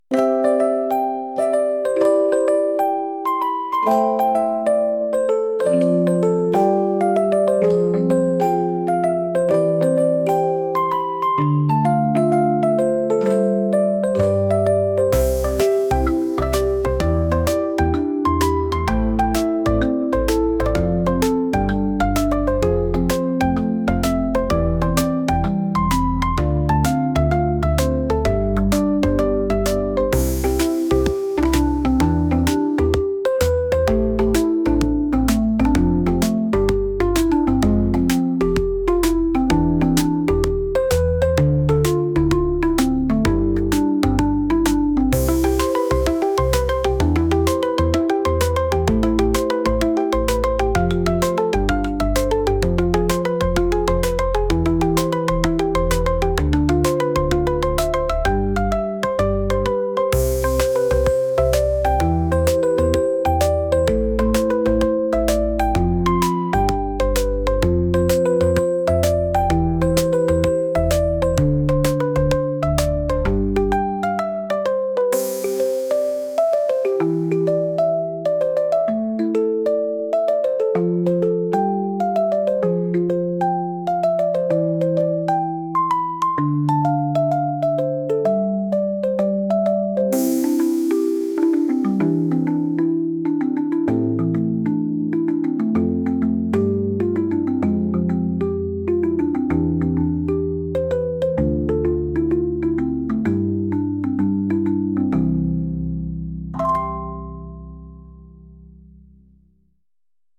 雨の雰囲気のようなオルゴール曲です 音楽素材（MP3）ファイルのダウンロード、ご利用の前に必ず下記項目をご確認ください。